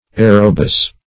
Meaning of aerobus. aerobus synonyms, pronunciation, spelling and more from Free Dictionary.
Search Result for " aerobus" : The Collaborative International Dictionary of English v.0.48: Aerobus \A"["e]r*o*bus`\, n. [A["e]ro- + bus.]